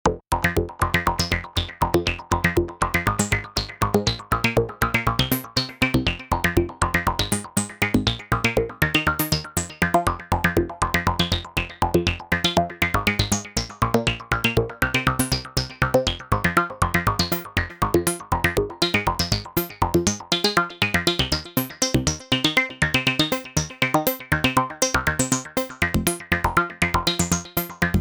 Sich wiederholende rhythmische gestufte Modulationen aus z.B. LFOs und Envelopes, quasi shift register oder auch quasi samplerate Reduzierung usw.
Zwei LFOs (nicht geclockt, beide werden nach 16 Steps geresettet) in einen Mischer und dann in ein S&H, das im Sequenzertakt geclockt wird. Das S&H moduliert dan Filter Cutoff.